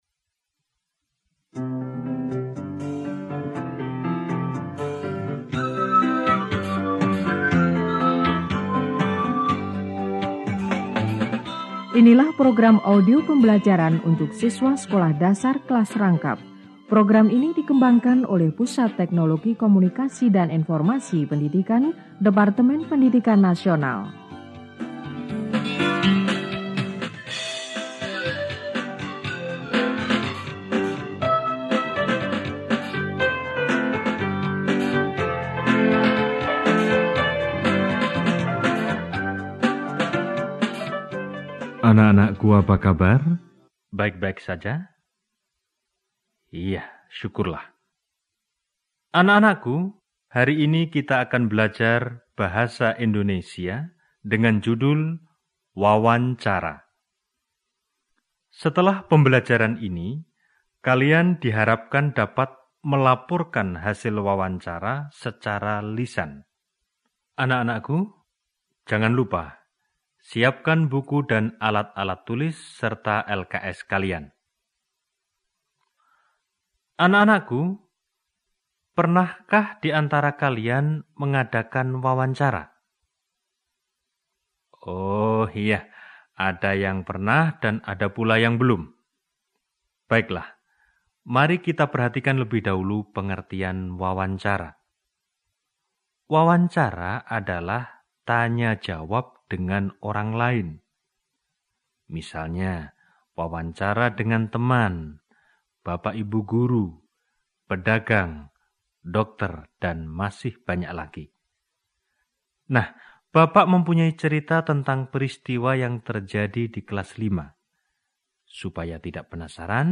SD -Audio : Wawancara_Kelas V_B.Indonesia.mp3